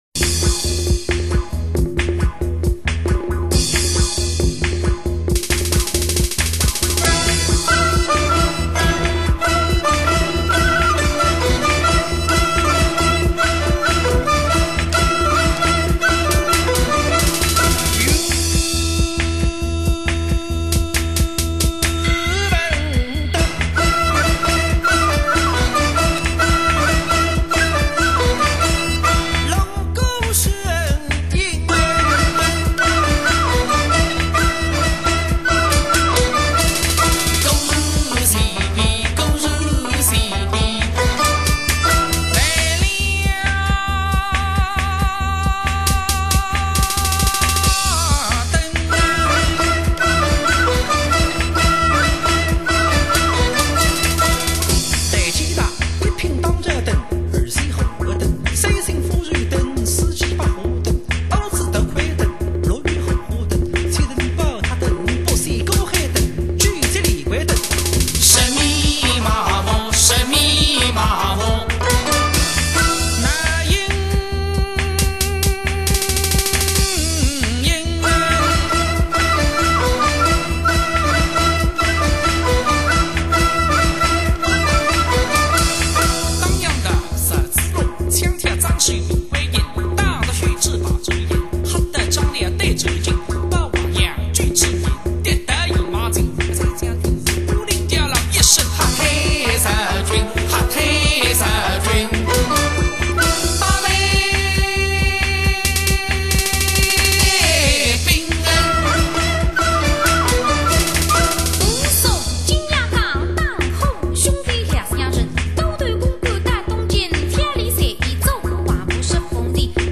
他们融合中国传统民族乐器与西方现代乐器，重现盛唐大国东西方音乐交融的宴乐气韵。